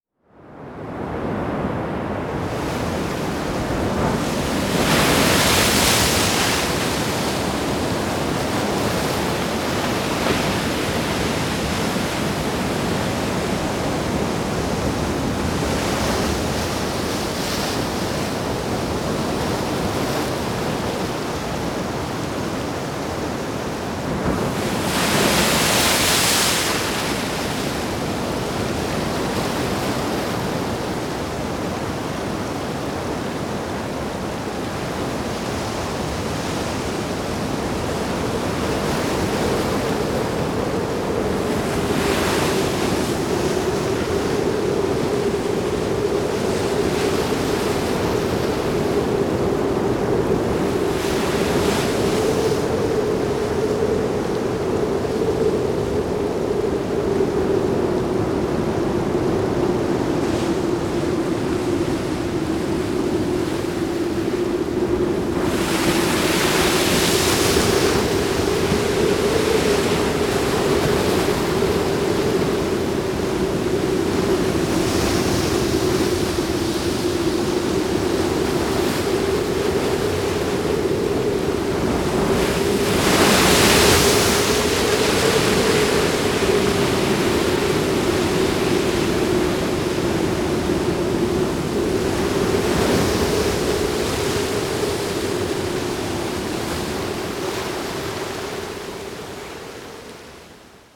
особенно,с шумом волн ...!
Хорошо с музыкой!
хорошо плещется.